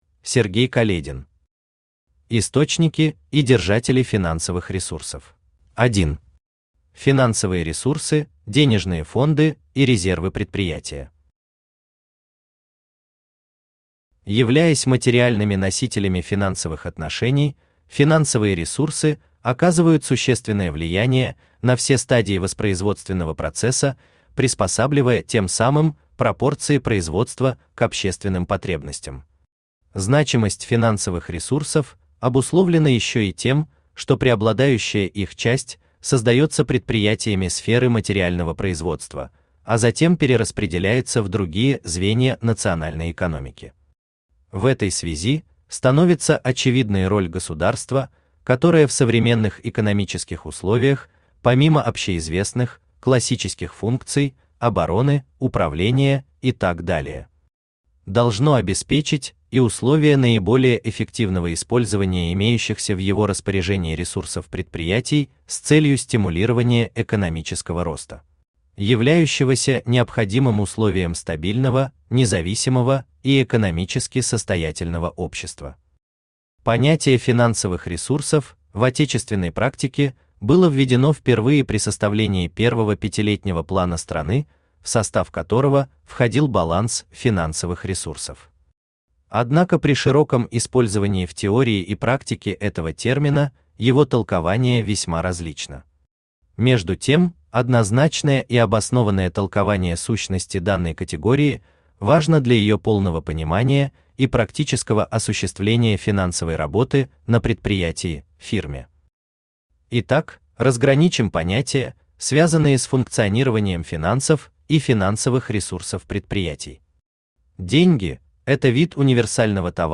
Аудиокнига Источники и держатели финансовых ресурсов | Библиотека аудиокниг
Aудиокнига Источники и держатели финансовых ресурсов Автор Сергей Каледин Читает аудиокнигу Авточтец ЛитРес.